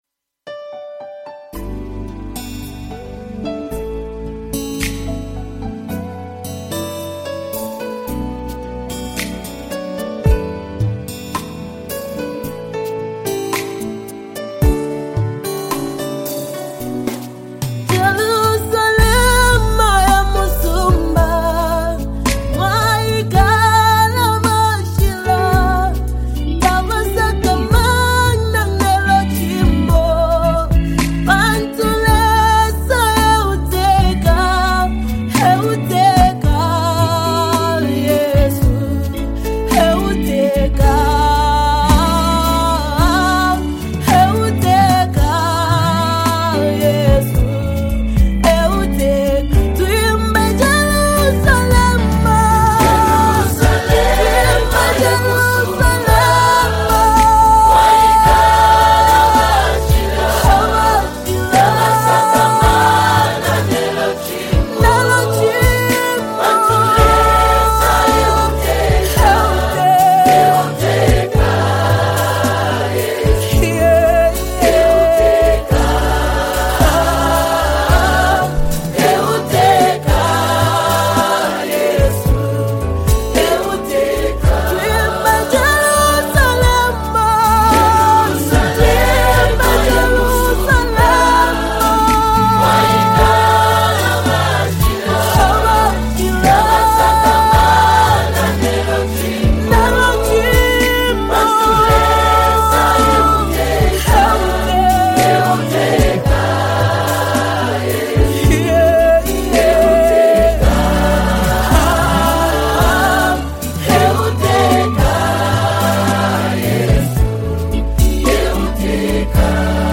Gospel Music
Known for her powerful vocals and soul-stirring lyrics